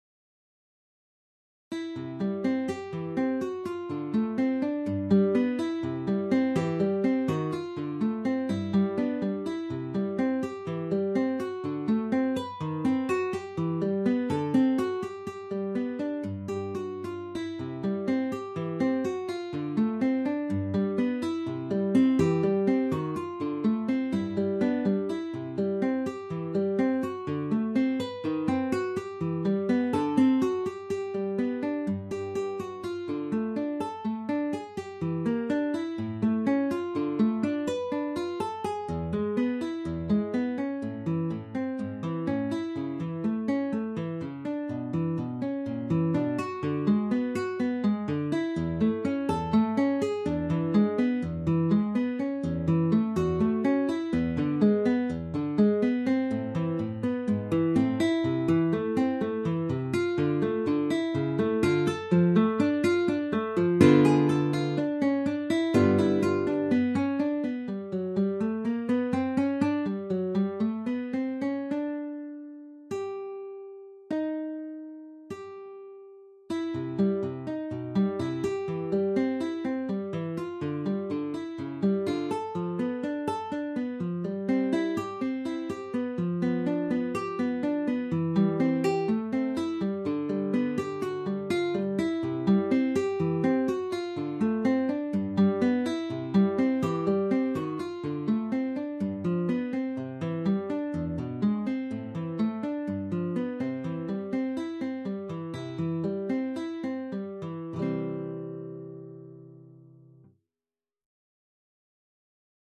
Insieme scrissero la raccolta di brani per chitarra ALBUMSBLADE, la cui parte preminente è di Frederik.